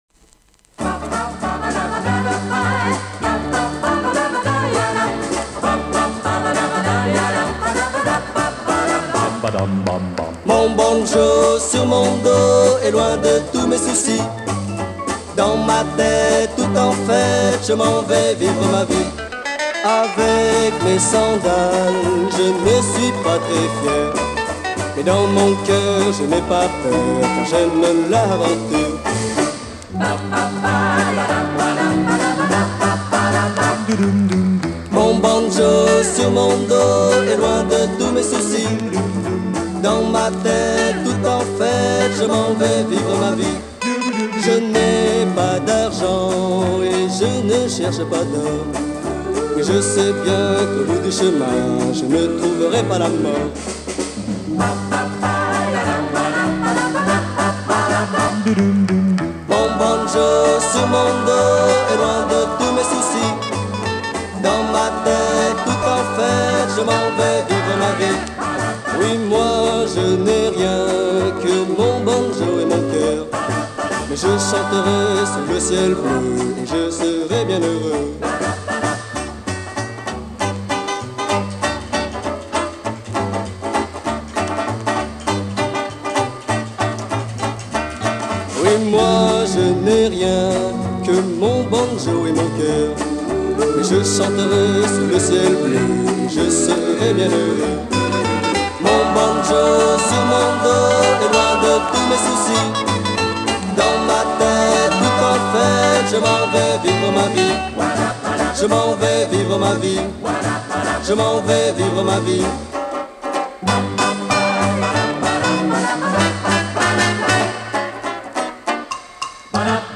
duo « rock »